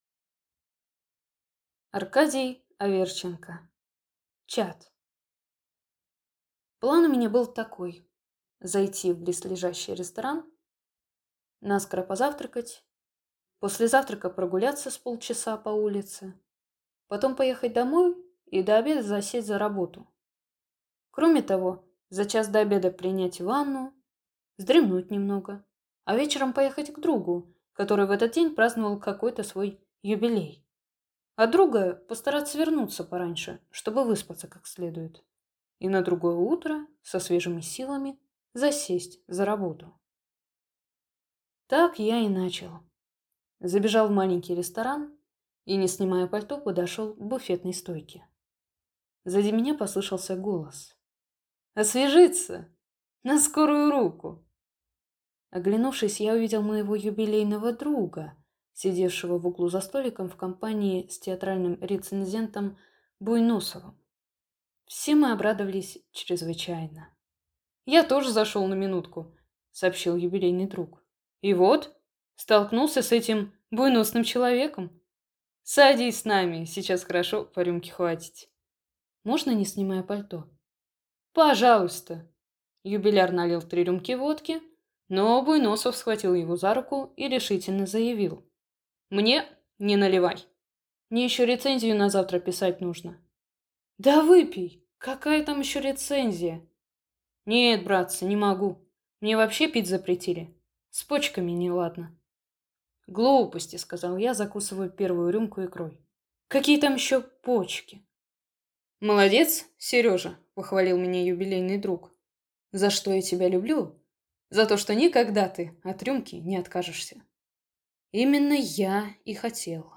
Аудиокнига Чад | Библиотека аудиокниг
Прослушать и бесплатно скачать фрагмент аудиокниги